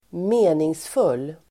Uttal: [²m'e:ningsful:]